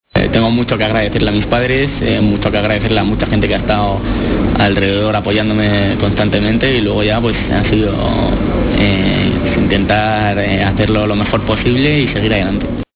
breve corte de sonido extraído de una entrevista en la Cadena Cope.